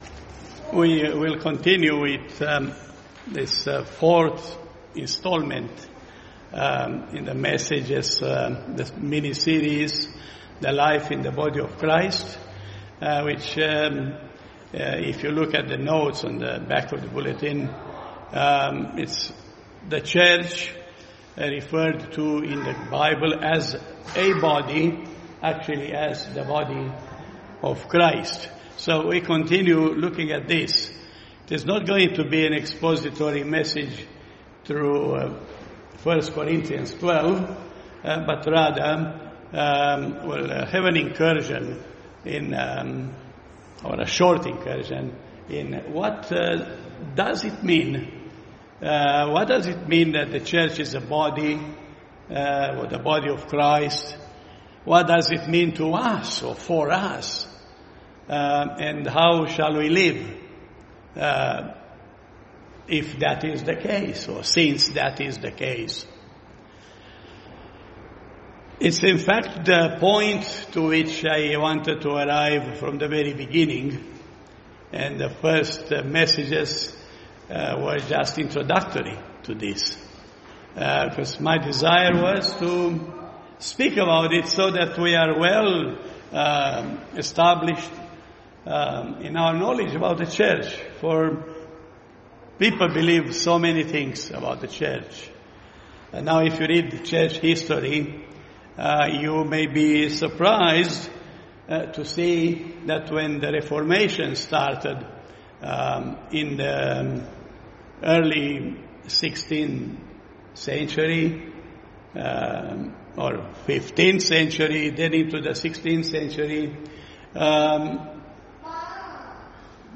Life in the Body of Christ Service Type: Sunday Morning « Life in the Body of Christ